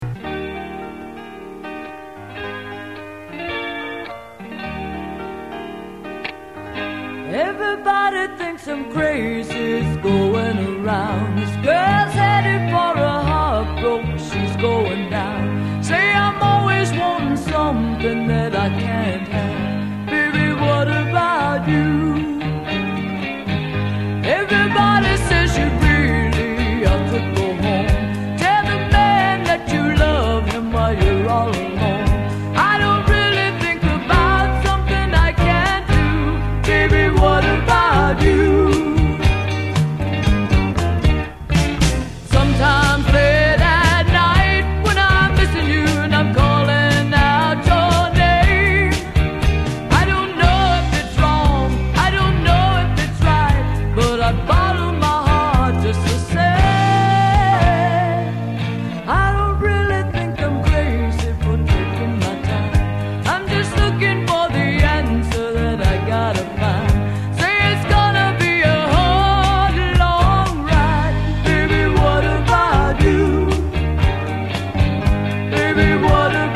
Country Stuff